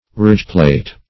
Ridgeplate \Ridge"plate`\, n.
ridgeplate.mp3